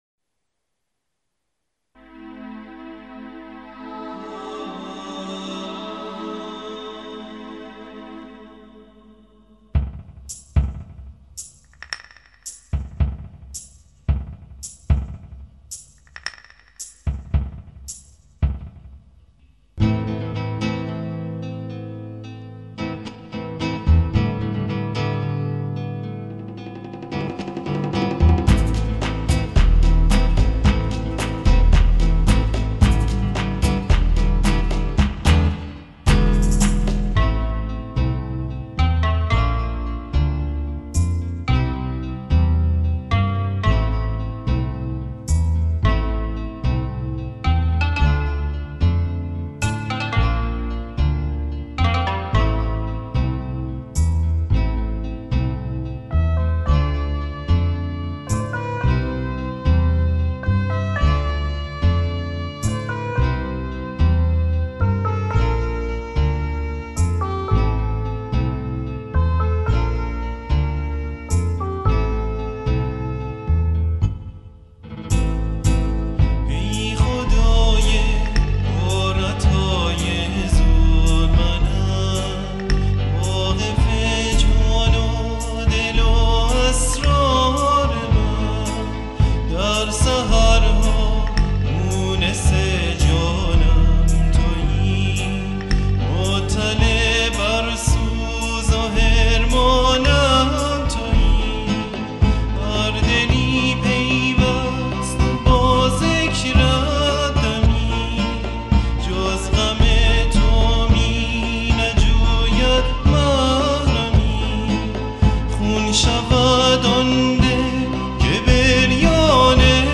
سرود - شماره 1 | تعالیم و عقاید آئین بهائی